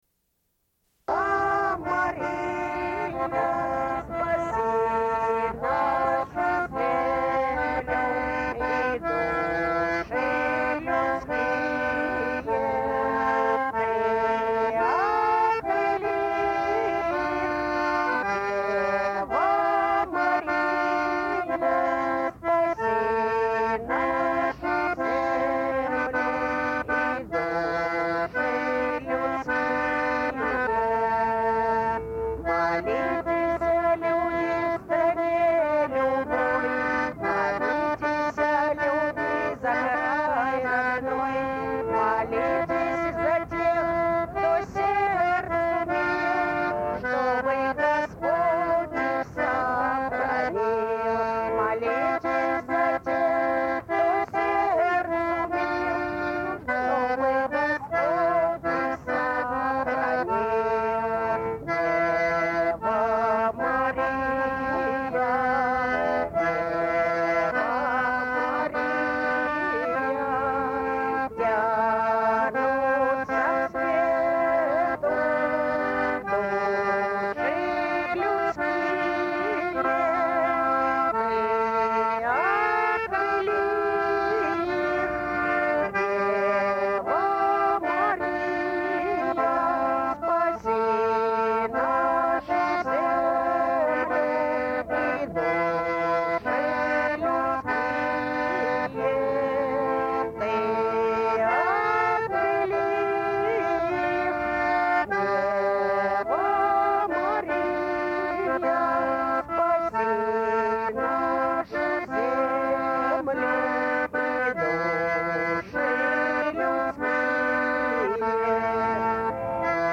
Elles diffusent des entretiens réalisés dans le train.
Une cassette audio, face A